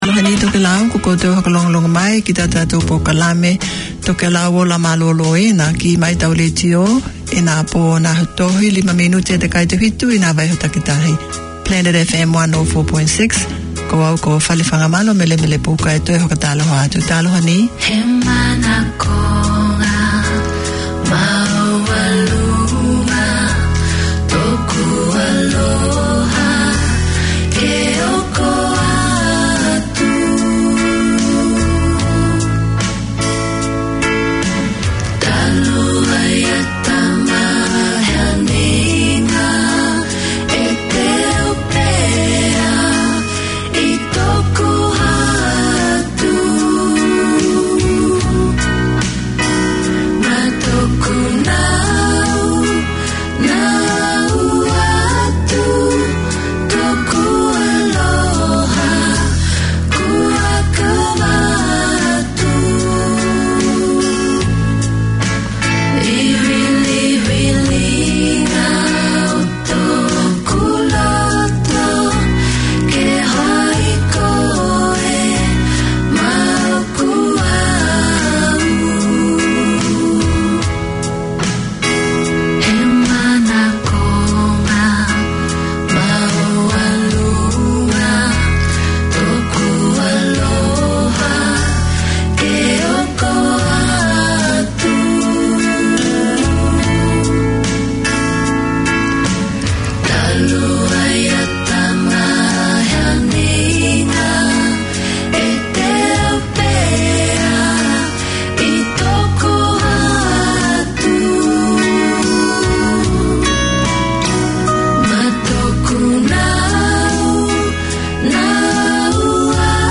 Te Ama Pasefika Health is promoting the well-being of Pacific people. Each week you’ll hear interviews with studio guests giving advice on health, education, employment and other support services that encourage wellness and foster healthy, happy lives for Pasefika people in New Zealand.